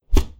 Close Combat Attack Sound 20.wav